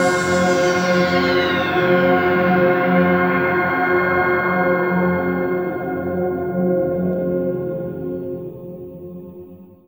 DM PAD1-13.wav